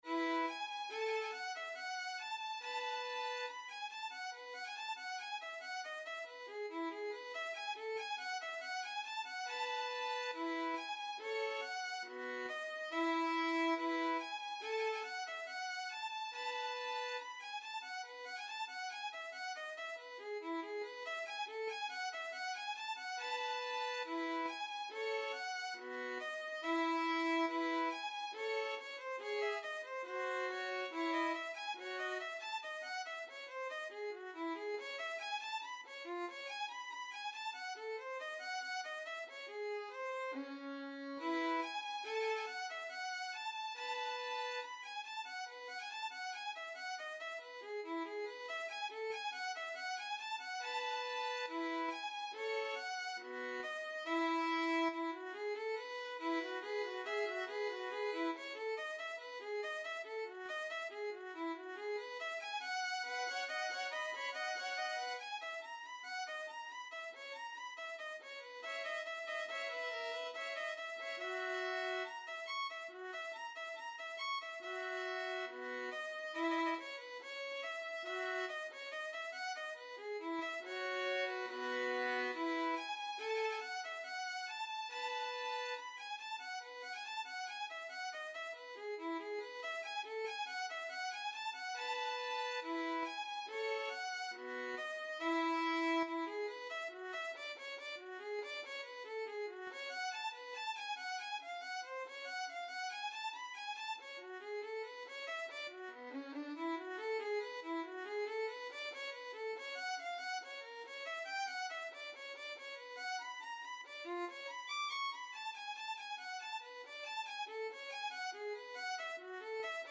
Violin version
2/2 (View more 2/2 Music)
B4-D7
Violin  (View more Advanced Violin Music)
Classical (View more Classical Violin Music)